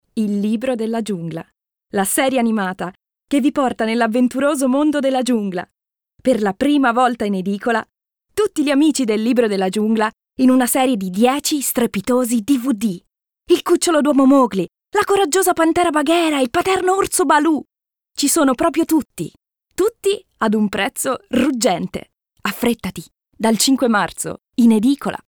Italienische Sprecherin/Synchronsprecherin, deutsch mit Akzent, Werbung,Hörbuch,Fitness,Industrie,e-lerning,Hörspiel, Voice-over, Moderatorin.
Sprechprobe: Werbung (Muttersprache):